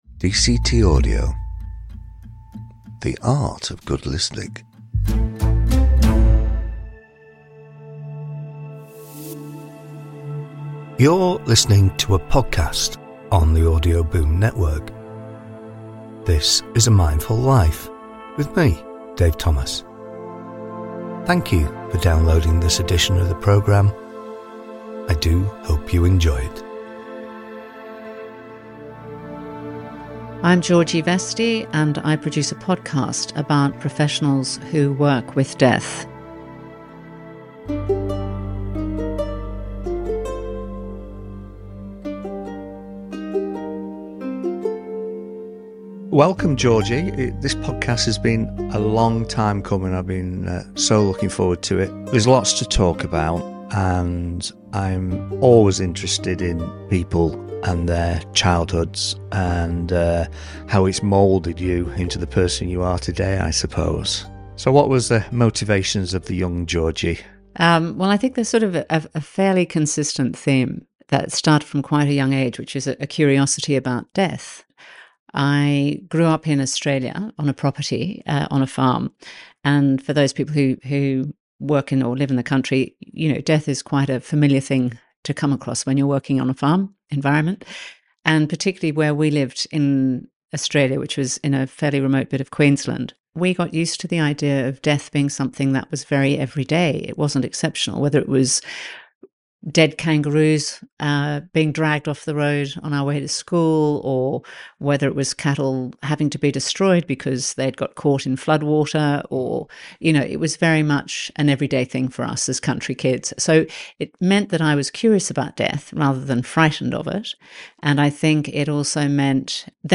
This is the second of a series of five podcasts recorded in London